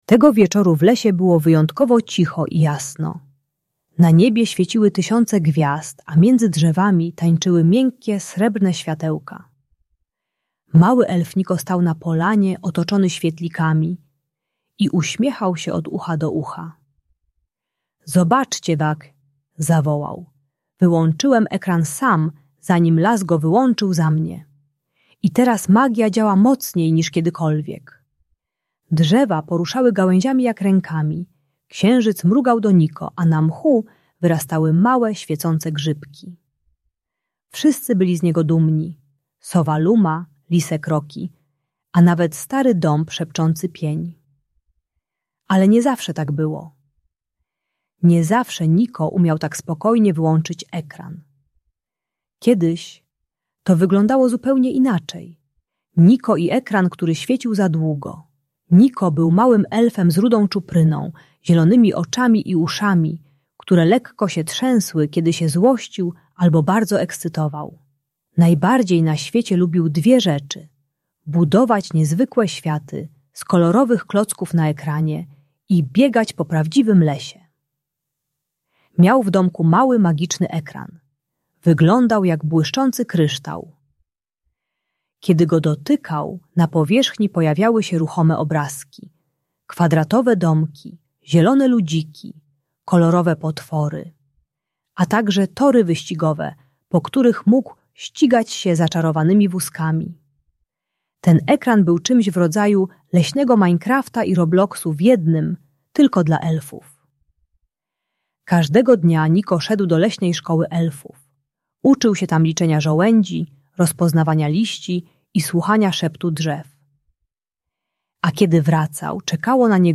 Audiobajka o odstawieniu ekranu bez krzyku.